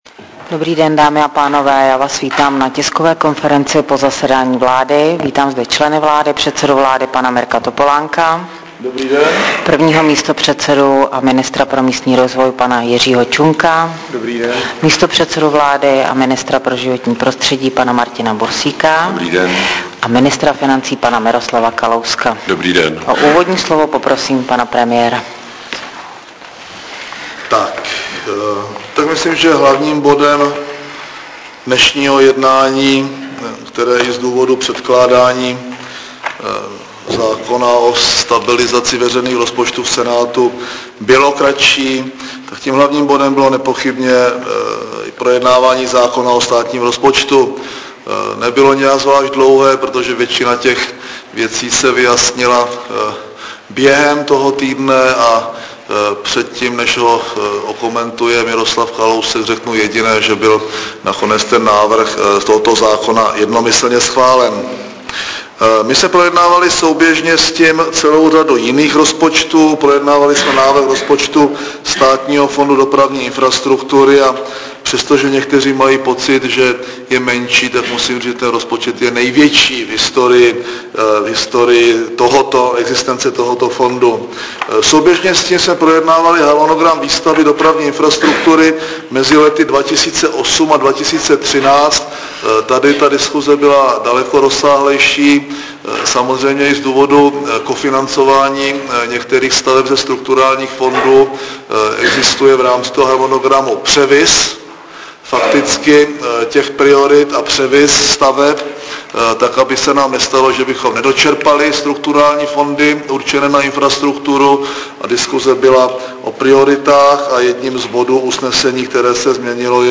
Tisková konference předsedy vlády ČR Mirka Topolánka po jednání vlády ve středu 19. září 2007